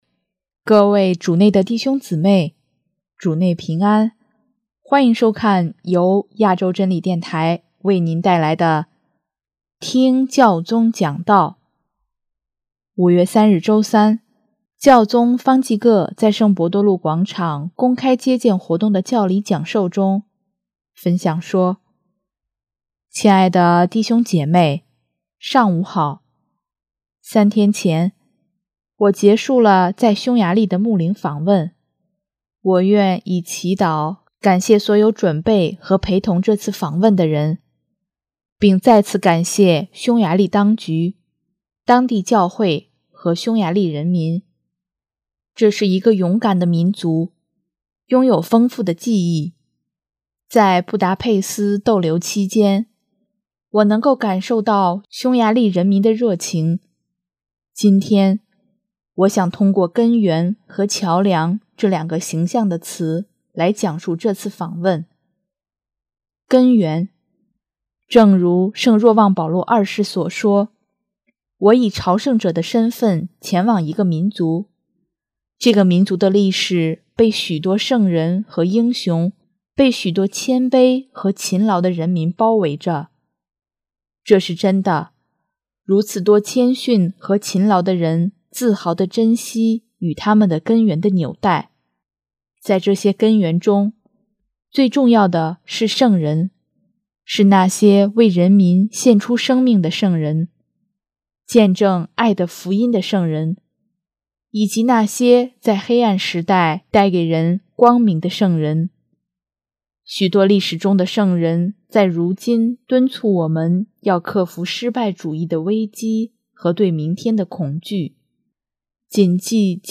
5月3日周三，教宗方济各在圣伯多禄广场公开接见活动的教理讲授中，分享说：